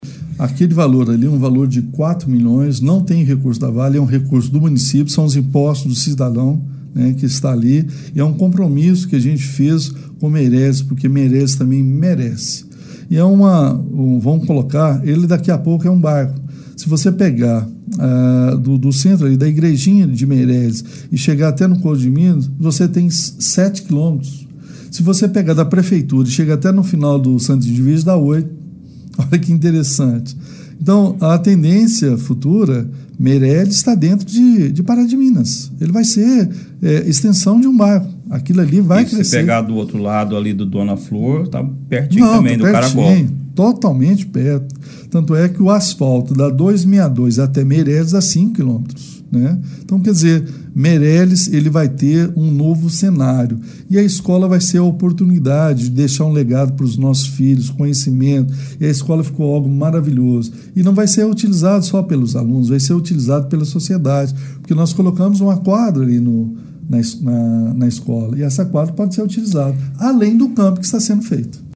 O prefeito também ressalta que todos os custos de construção e mobiliário da escola de Meireles serão bancados com recursos do Executivo Municipal, sem utilizar o dinheiro que a comunidade recebeu como reparação por transtornos causados pelas obras de construção da adutora do Rio Pará: